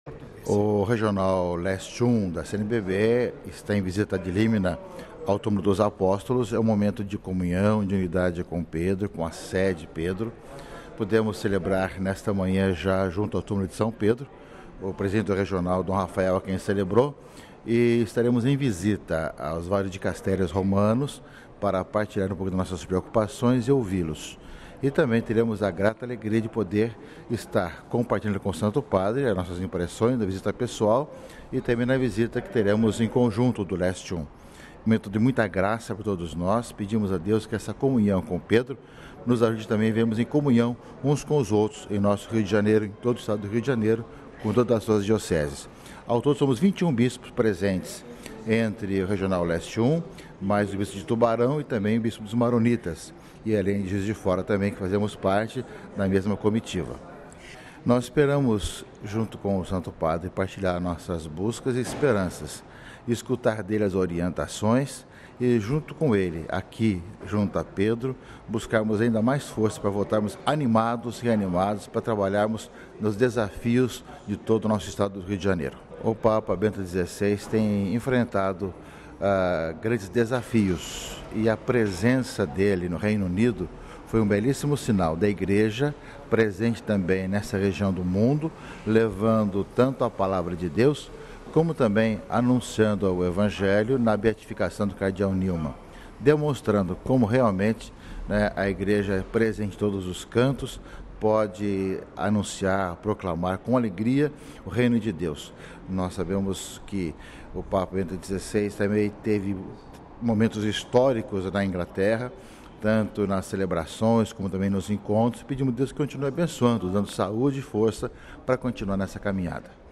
A Rádio Vaticano conversou nesta manhã com o Arcebispo do Rio de Janeiro, Dom Orani João Tempesta que está participando da visita ad Limina.